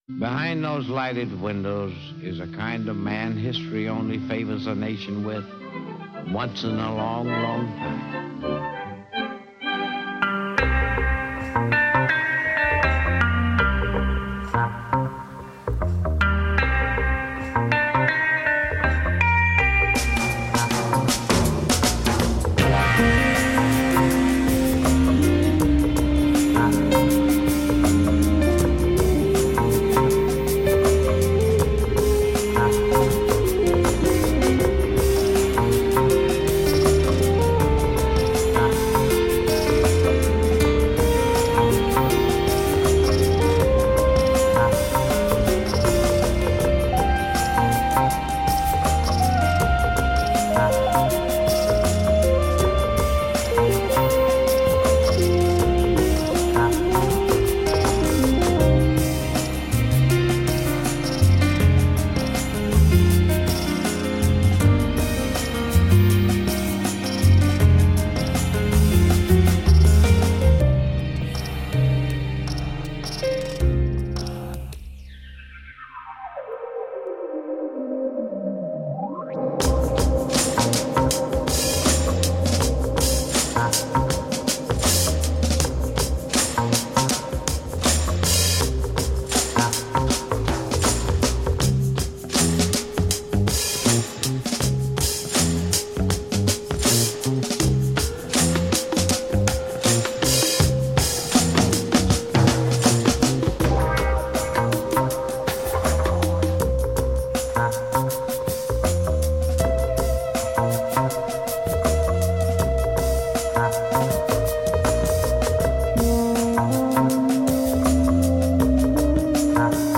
Groove laden chillout funk.
Tagged as: Jazz, Funk, Chillout